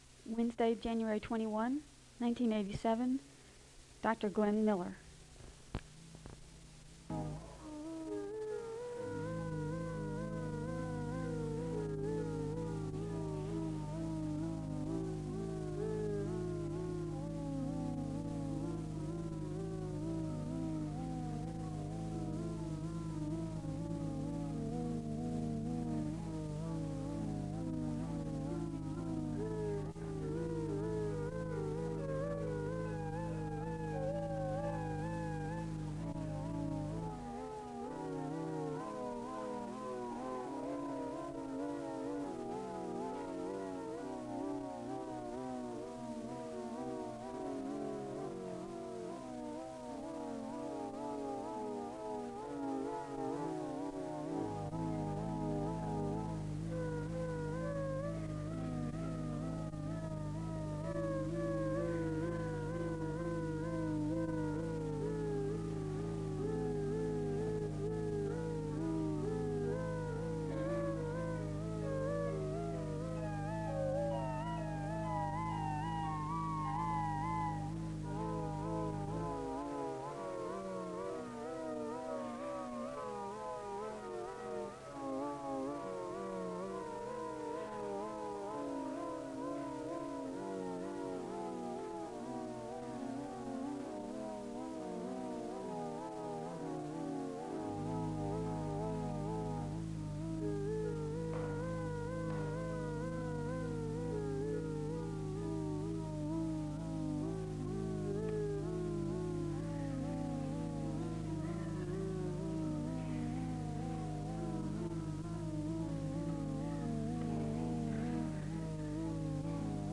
The service begins with organ music (00:00-02:15).
A speaker reads from Psalm 24 (13:21-14:52). The choir sings a song of worship, and the song is followed by a word of prayer for the Southeastern community (14:53-23:12).
The beginning of the recording for the January 22, 1987 chapel service is attached on the end. The service begins with organ music (26:01-29:00). A speaker reads from Matthew’s gospel, and he gives a word of prayer.